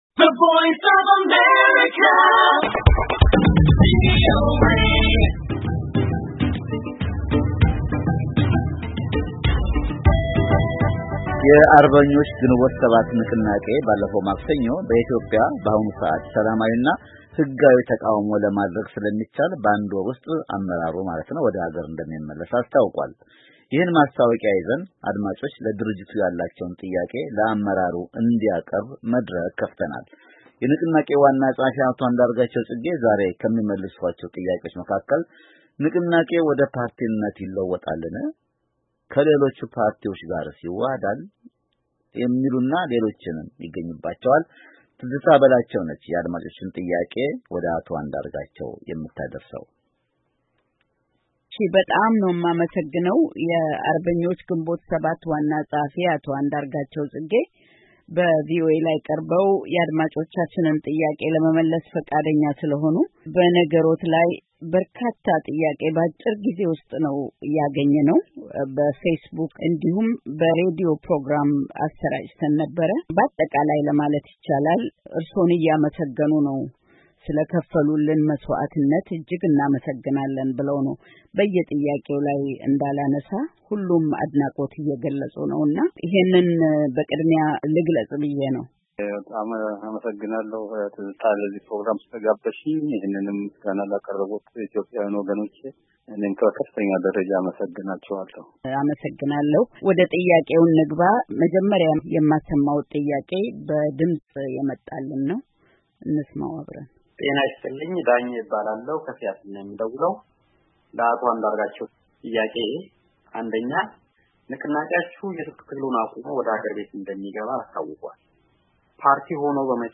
አቶ አንዳርጋቸው ፅጌ በ"ለጥያቄዎ መልስ" ፕሮግራም ከአድማጮች ለቀረበ ጥያቄ የሰጡት ምላሽ -ክፍል አንድ